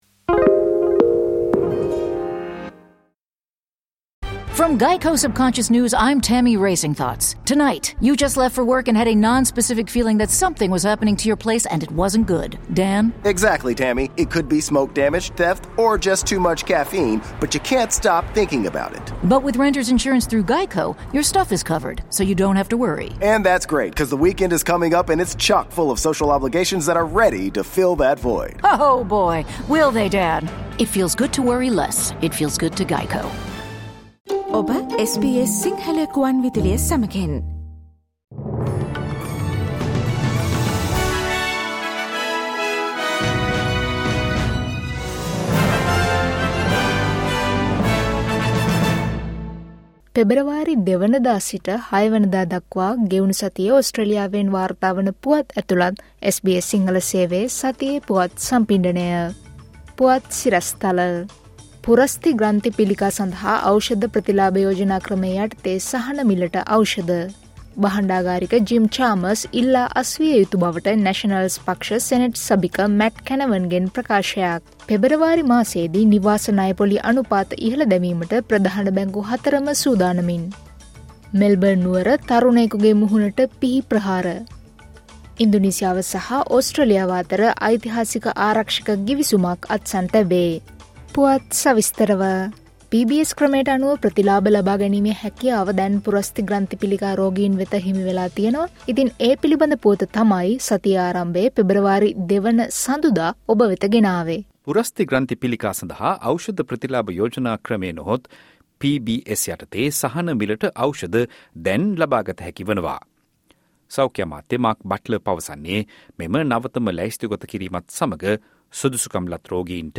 පෙබරවාරි 02වන දා සිට පෙබරවාරි 06වන දා දක්වා ගෙවුණු සතියේ ඕස්ට්‍රේලියාවෙන් වාර්තා වන පුවත් ඇතුළත් SBS සිංහල සේවයේ සතියේ පුවත් ප්‍රකාශයට සවන් දෙන්න.